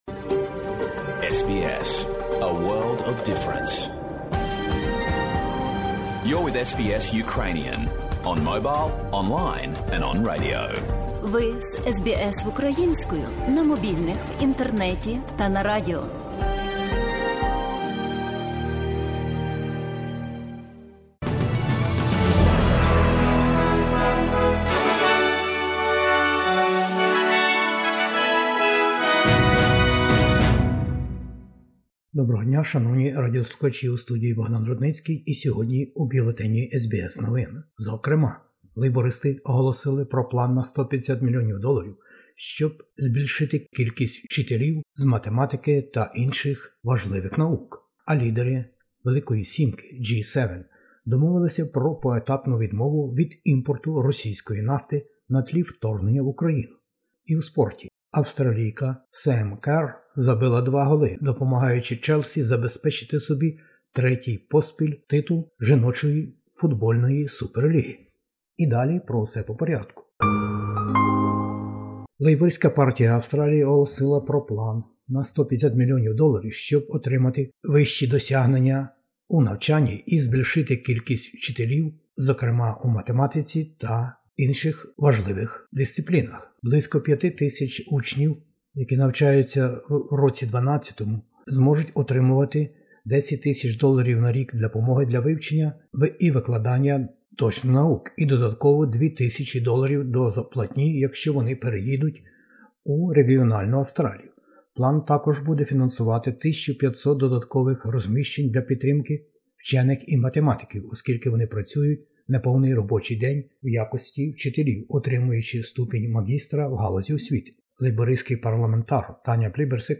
Бюлетень SBS новин українською мовою щодня.